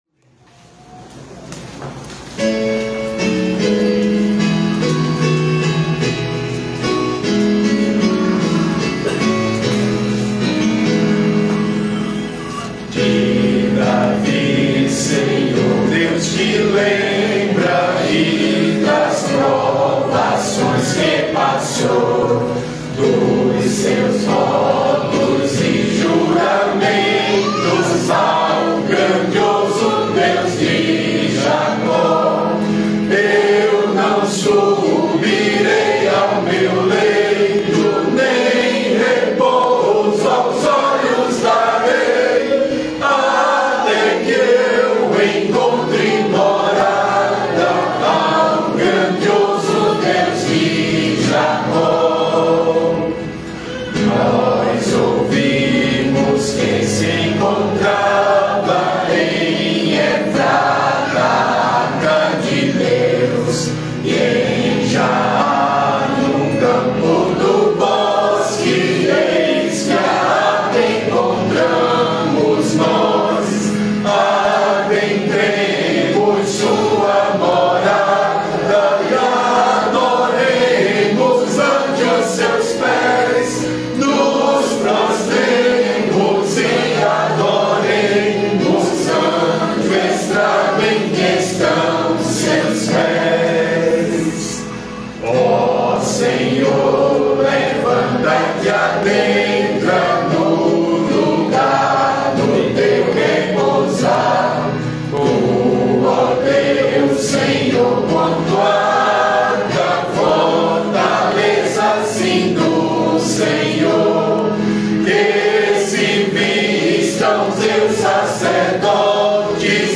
salmo_132B_cantado.mp3